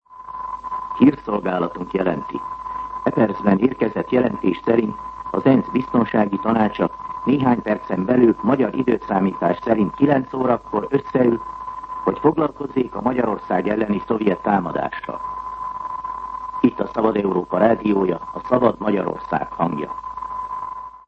MűsorkategóriaHírszolgálat